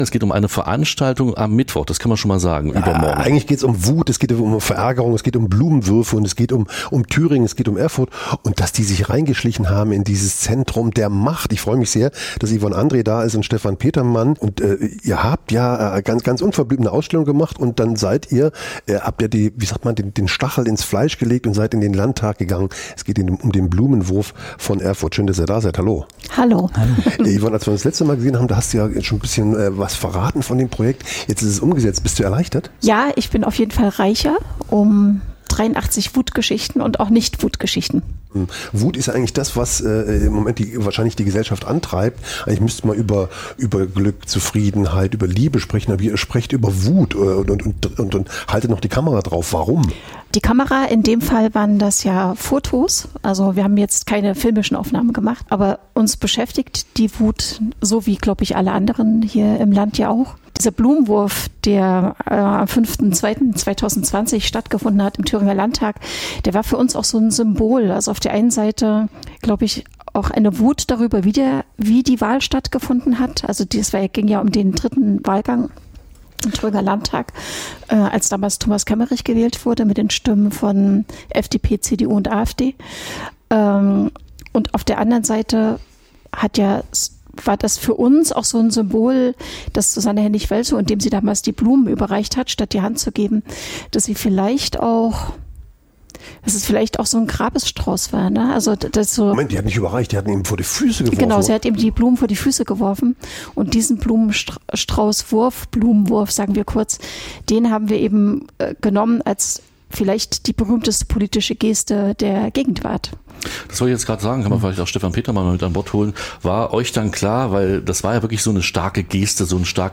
Das Gespr�ch